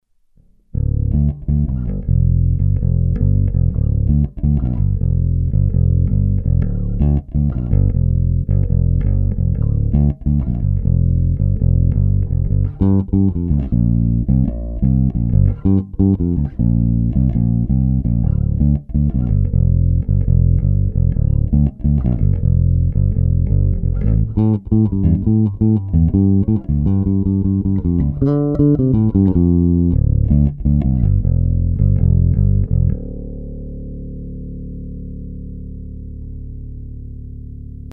Here are few recordings of the blue bass guitar sounds, tuned in to a Behringer UB802 mixing desk, with a Yamaha DS-XG sound card (DAC 16bit/48kHz , S/N 85dB) and the Windows XPpro recorder (without compression).
Basse bleue, 2 micros série ; Blue bass, all pickups in series.
MP3 44,1kHz 128kb/s ; mono
basse_1_serie.mp3